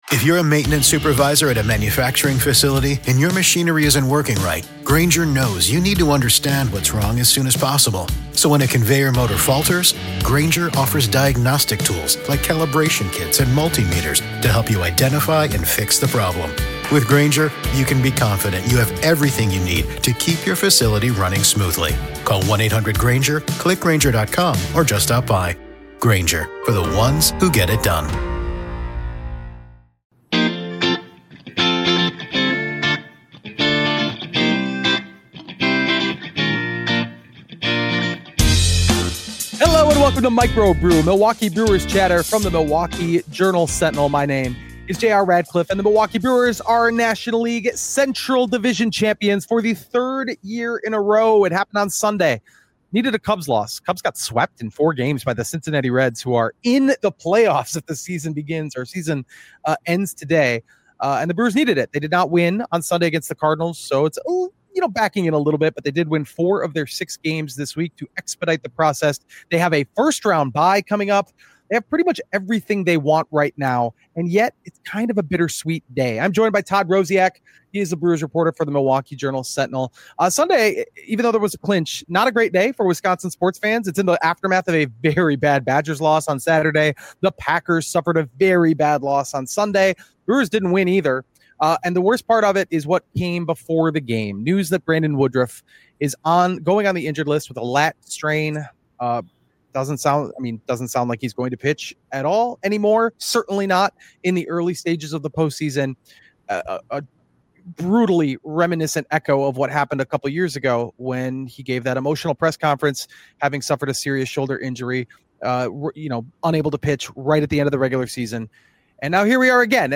A great week ends in a clinch, but there's a really big catch. Plus: An interview with Jackson Chourio (09.22.2025)